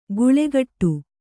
♪ guḷegaṭṭu